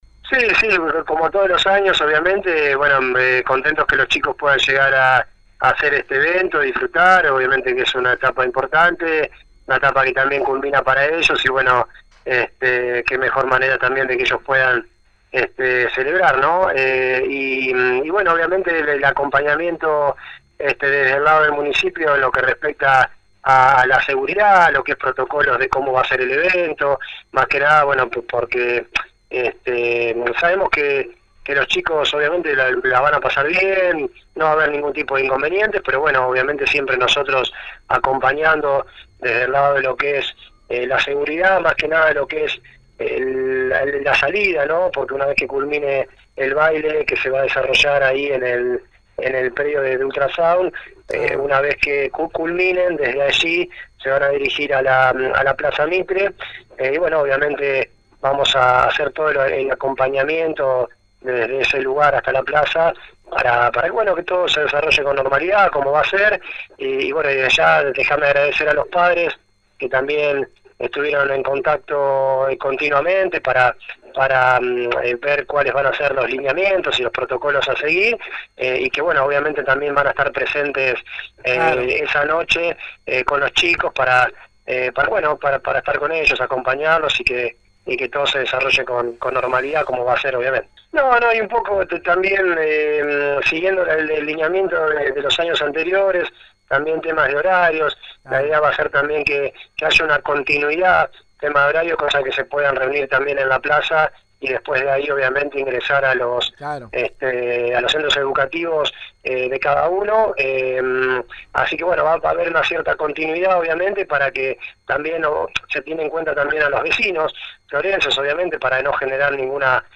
(foto archivo) El subsecretario de Gobierno municipal, Dr. Ricardo Spinelli, habló este martes con la 91.5 sobre el tradicional festejo de los estudiantes del último año del nivel secundario en el inicio del ciclo lectivo.